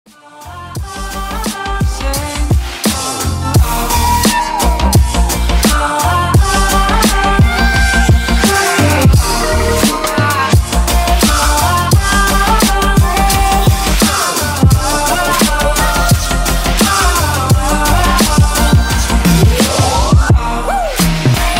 Elektronisk musik, Android